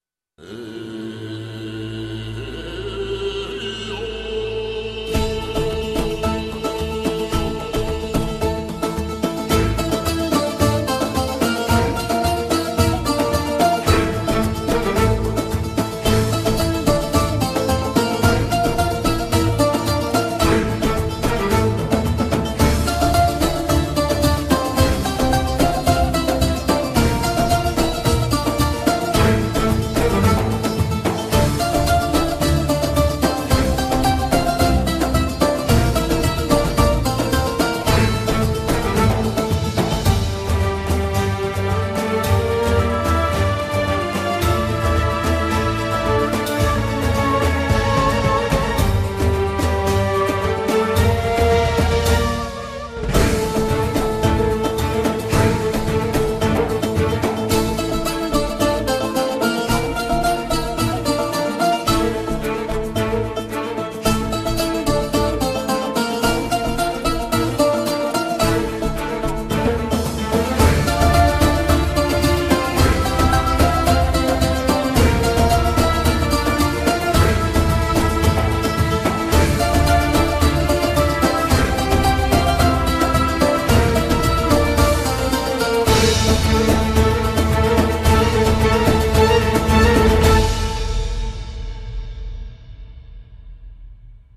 Yaylılar ve Ud
Dombra ve Telliler
Üflemeli Çalgılar
Vokal
Vurmalı Çalgılar